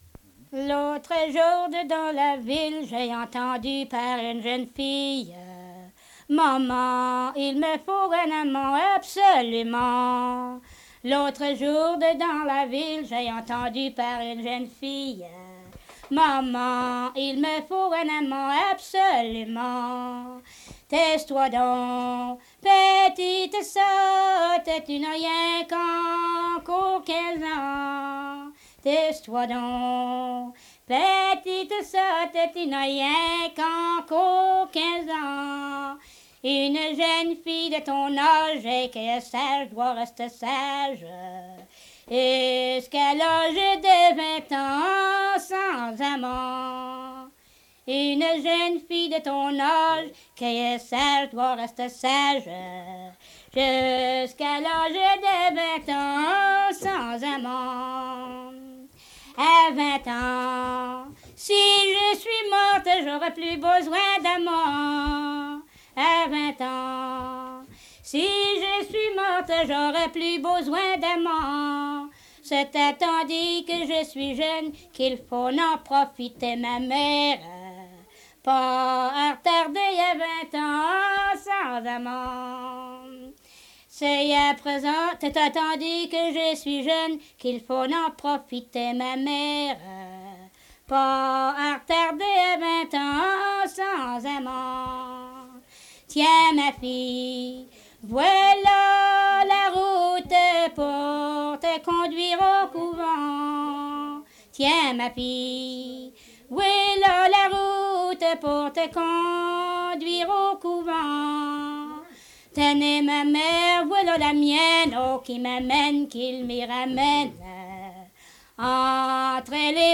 Chanson
Emplacement Cap St-Georges